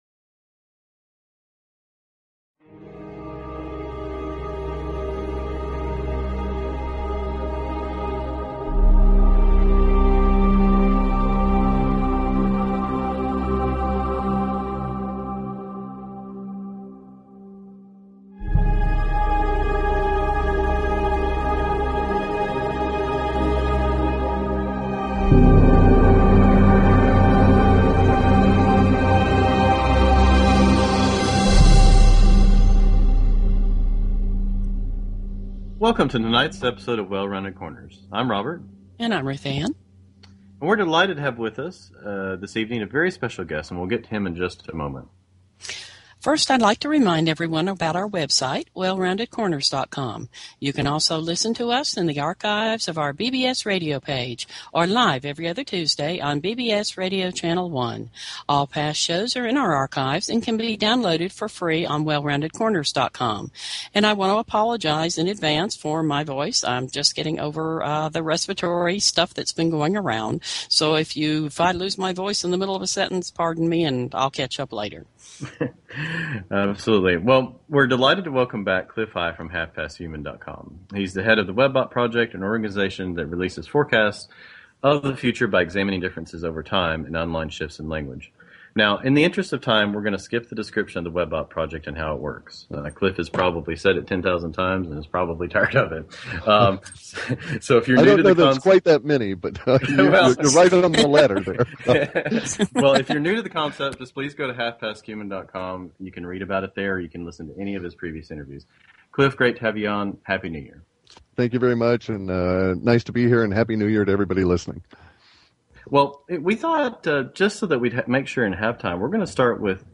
Talk Show Episode, Audio Podcast, Well_Rounded_Corners and Courtesy of BBS Radio on , show guests , about , categorized as